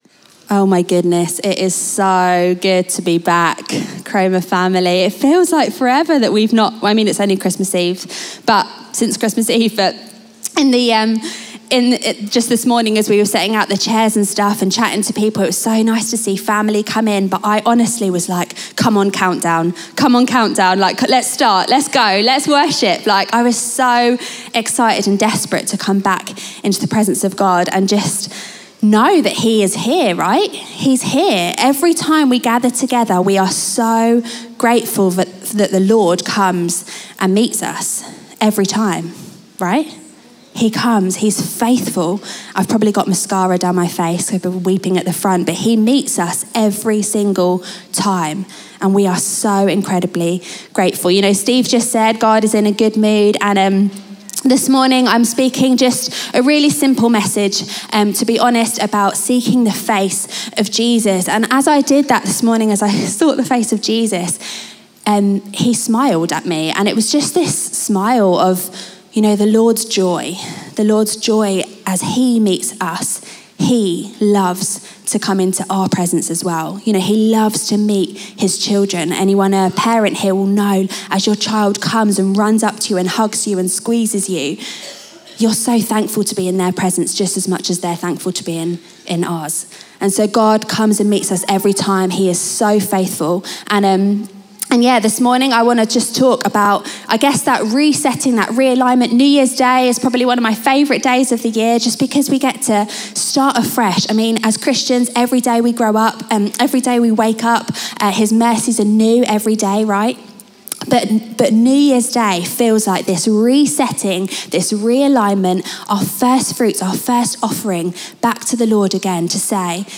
Chroma Church - Sunday Sermon 2023 – Seek His Presence!